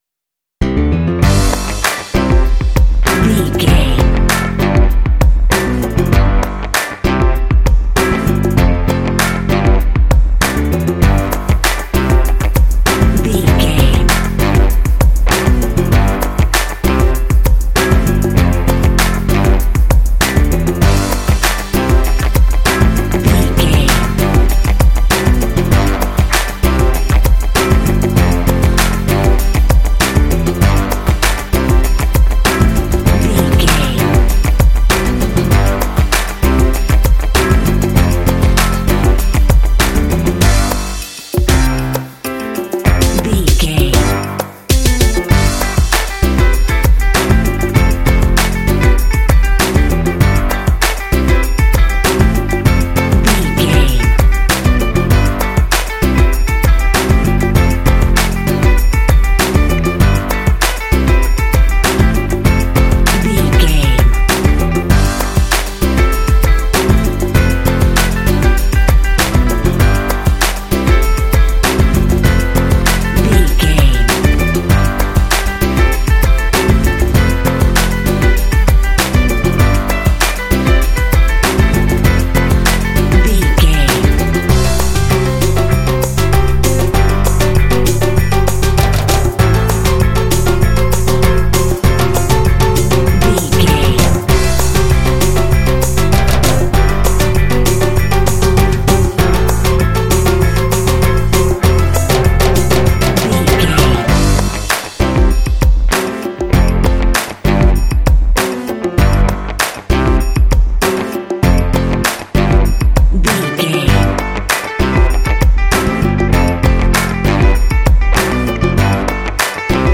This cute hip hop track is great for kids and family games.
Uplifting
Ionian/Major
bright
happy
bouncy
piano
bass guitar
electric organ
drums
electric guitar
strings
Funk